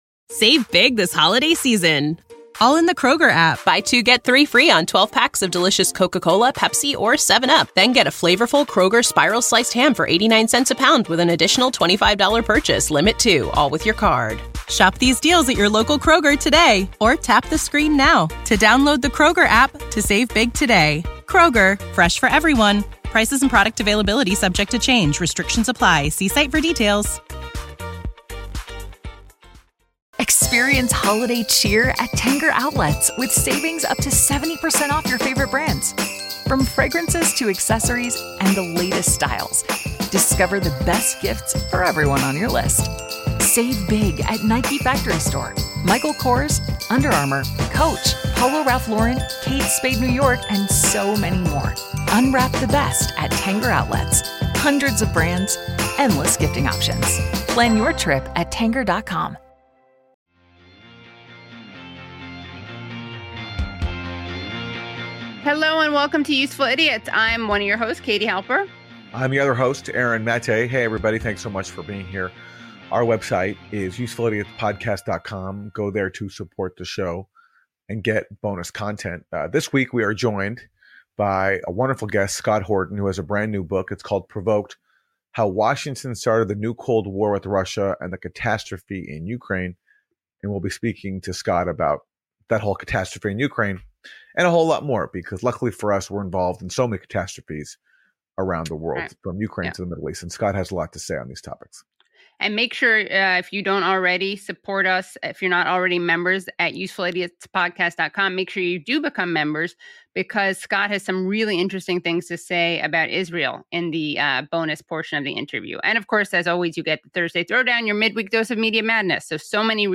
Useful Idiots is an informative and irreverent politics podcast with journalist Aaron Maté and podcaster/writer Katie Halper. Episodes feature analysis of the political news of the week and exclusive interviews, with humor, commentary and dissection of why both Republicans and Democrats suck.